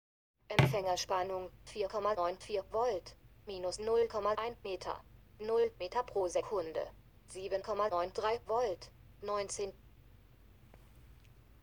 Die Empfängerspannung wird mit Präfix "Empfängerspannung" und Wertangabe (z.B. 4,95V) angesagt.
Die beiden Werte vom oXs werden ohne Präfix angesagt (nur die reinen Werte).
Möchte mein zuletzt geäußertes Problem noch mit einer Sprach-Datei verdeutlichen.
Zusätzlich ist mir aufgefallen das der Temperaturwert sogar ohne Einheit angesagt wird (letzter Wert).
SprachausgabemitoXs.wav